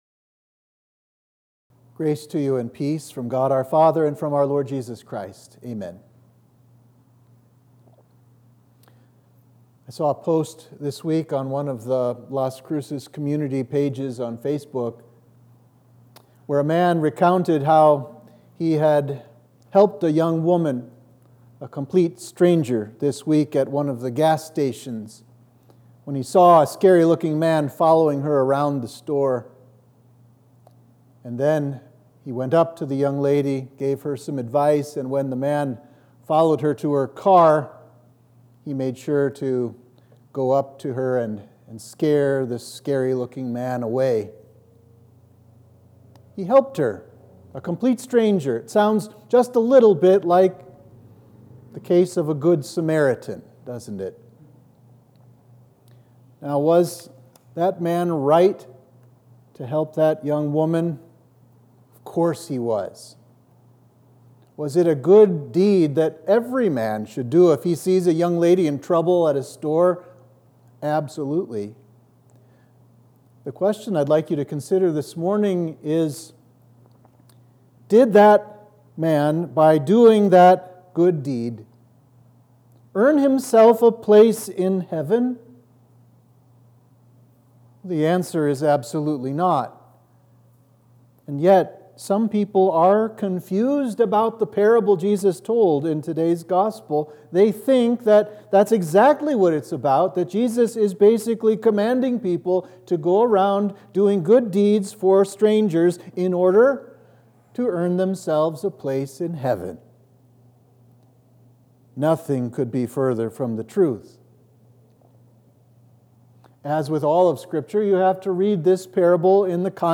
Sermon for Trinity 13